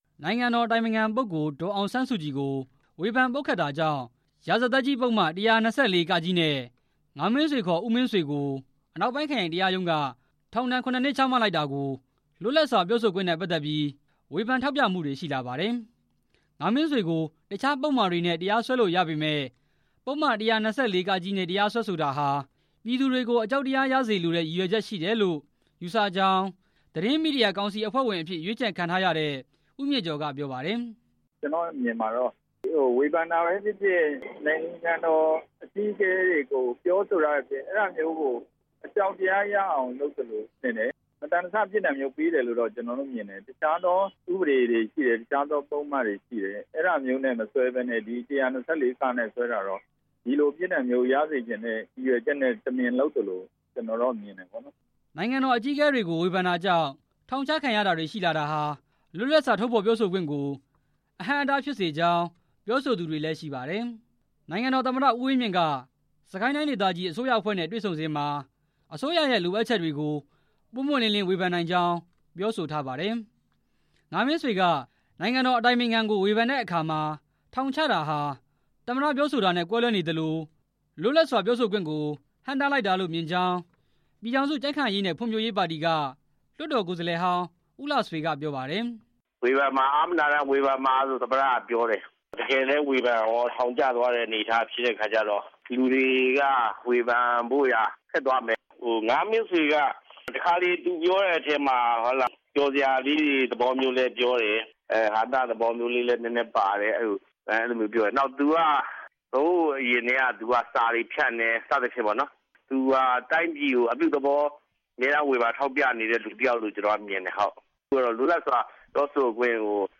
ဒီကိစ္စအပေါ် သတင်းမီဒီယာသမားတွေ၊ လွှတ်တော် ကိုယ်စားလှယ်ဟောင်းတွေနဲ့ လူ့အခွင့်အရေးကော်မရှင်က တာဝန်ရှိသူတွေရဲ့ အမြင်ကို